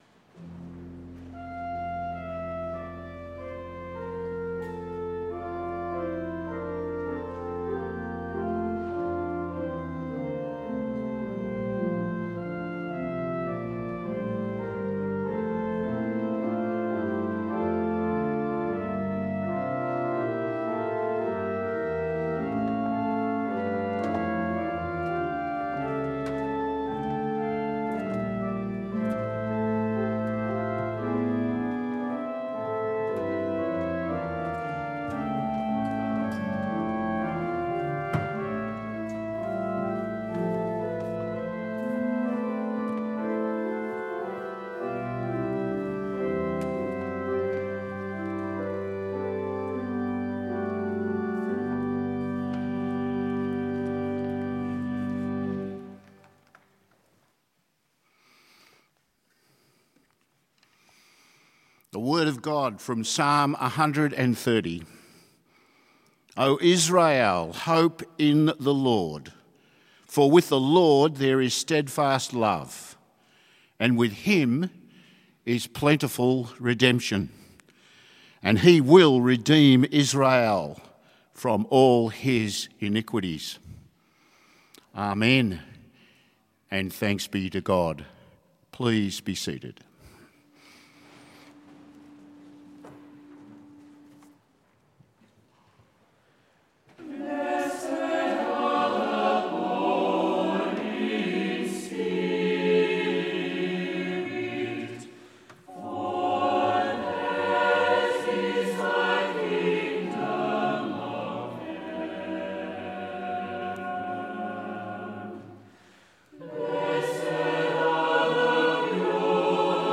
Full Service Audio
The Scots’ Church Melbourne 11am Service 7th of March 2021